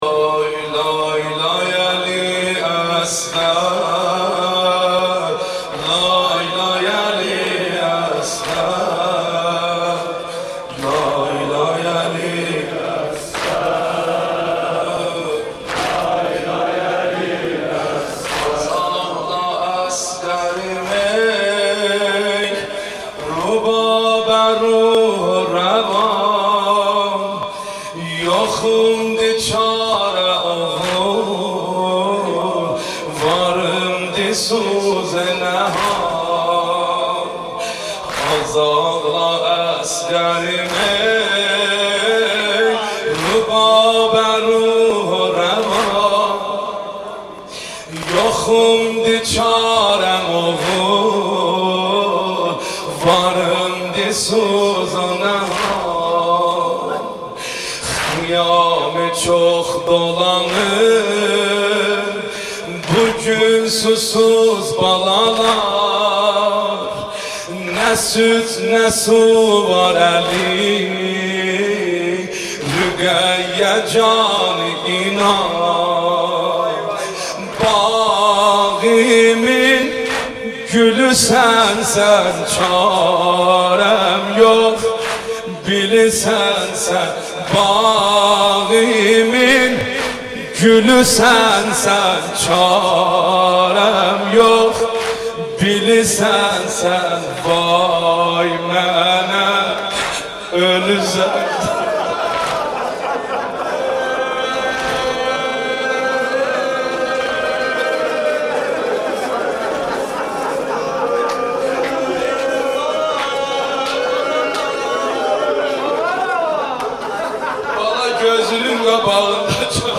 سینه زنی شب هشتم محرم ۹۸